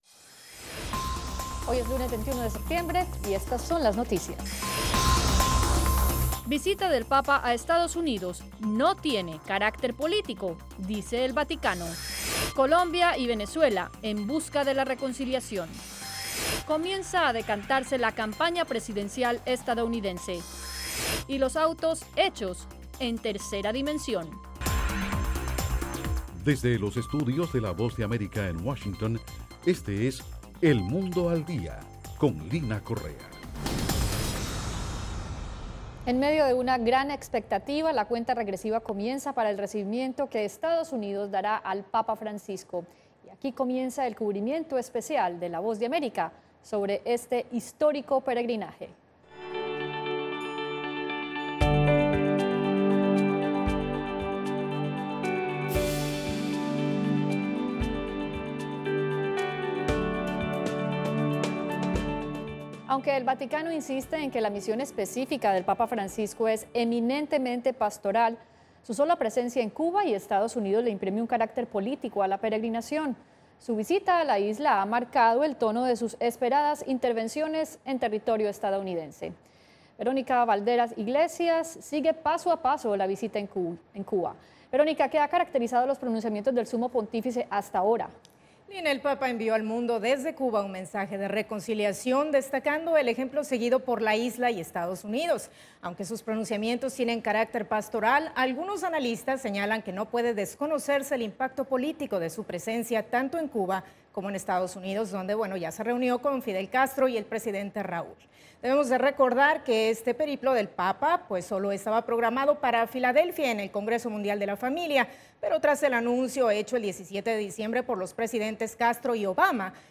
Una transmisión simultanea del noticiero de televisión “El mundo al día” en radio.